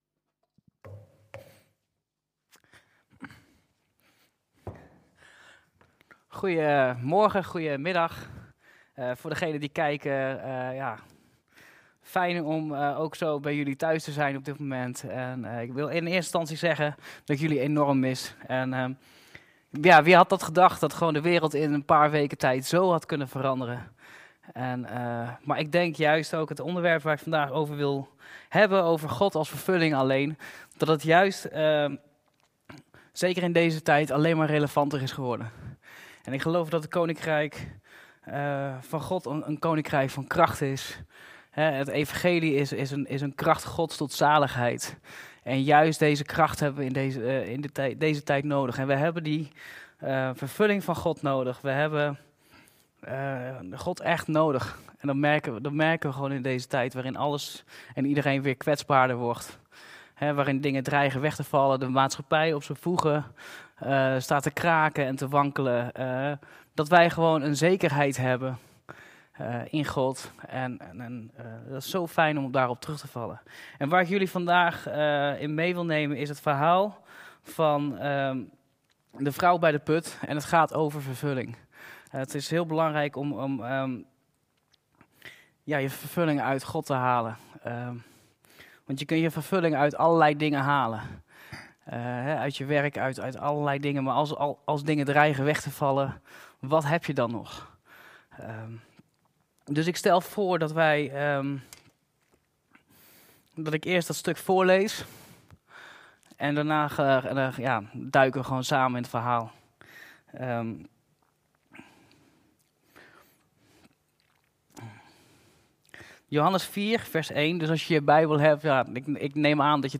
Toespraak 2 mei: Vervulling; Gave van God alleen - De Bron Eindhoven